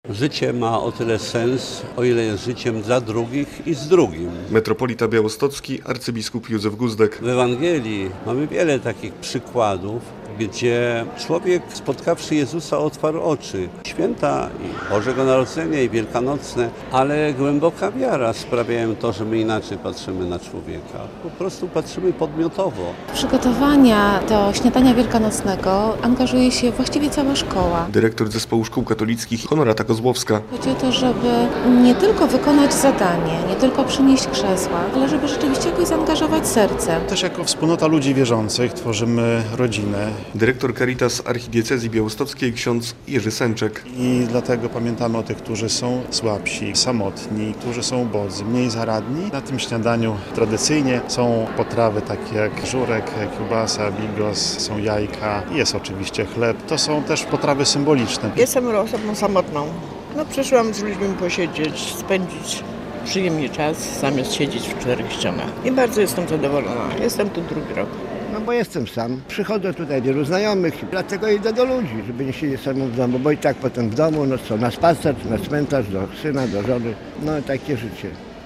Śniadanie wielkanocne dla potrzebujących i samotnych w Białymstoku - relacja
Śniadanie poprzedziła wspólna modlitwa i życzenia.